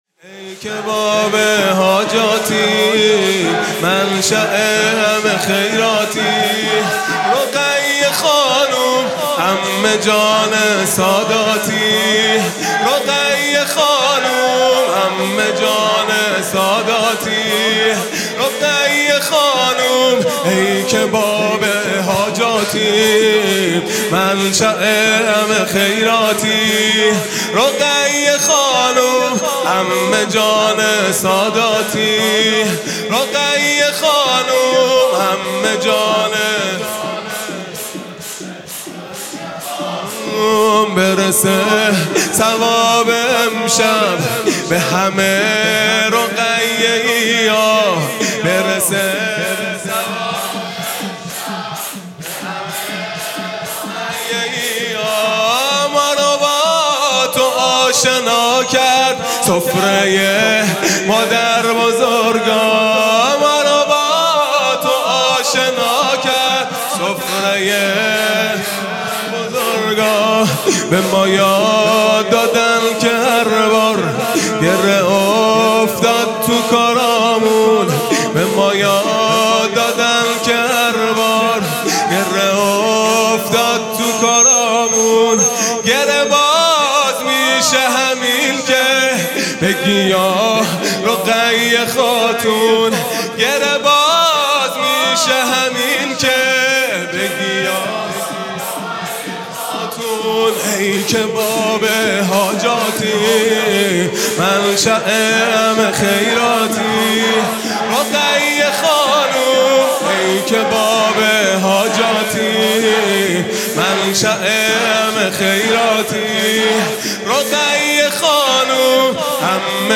خیمه گاه - هیئت بچه های فاطمه (س) - شور | ای که باب حاجاتی ، منشأء همه خیراتی | ۲۹ تیرماه ۱۴۰۲